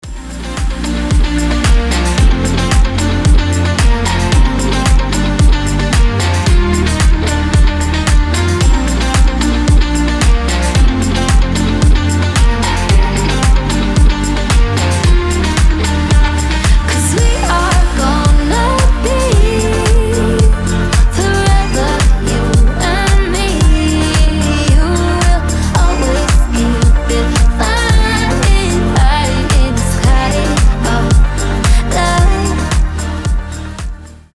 женский вокал
Dance Pop
Cover
приятные
house
теплые